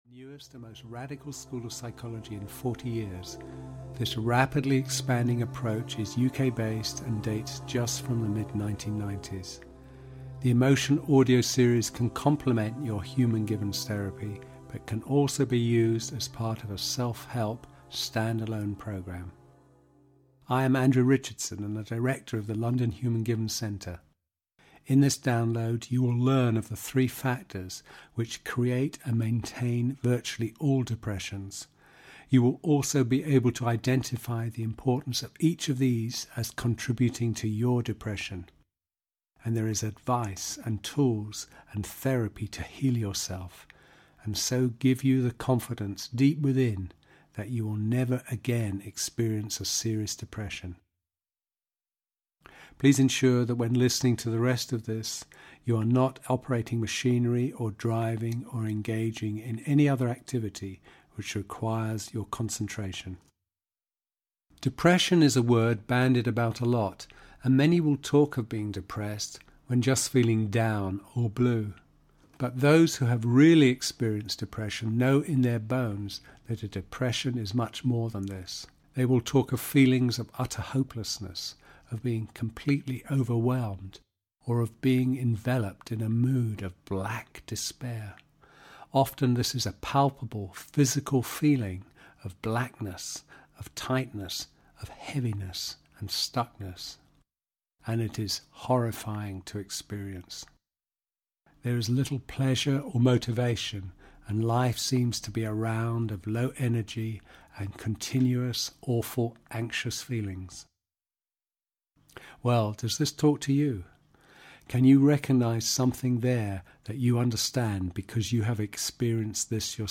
Overcoming Depression (EN) audiokniha
Ukázka z knihy